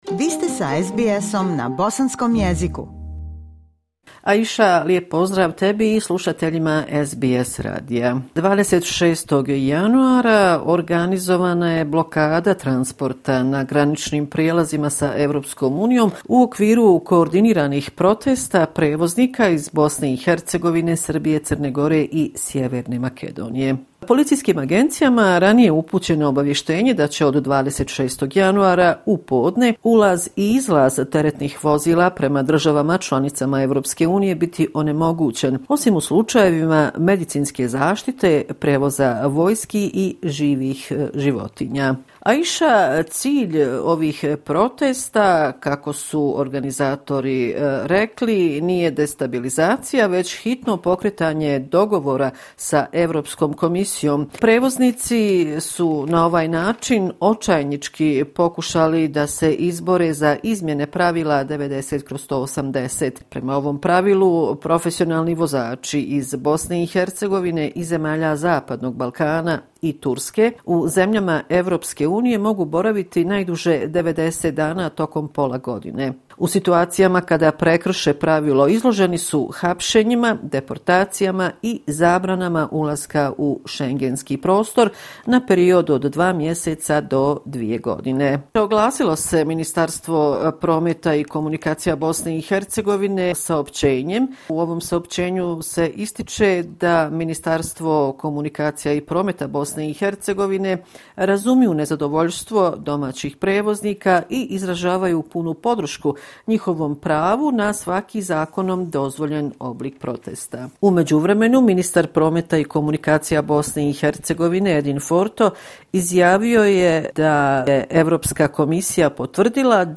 Sedmični izvještaj